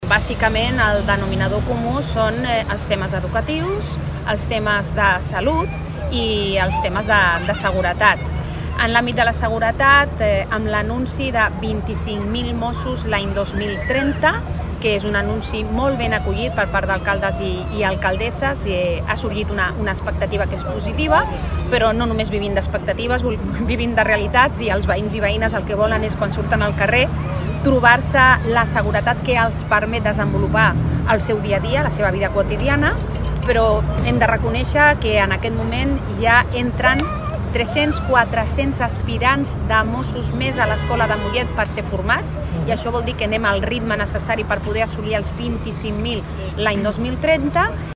Pilar Díaz, delegada del Govern Generalitat a Barcelona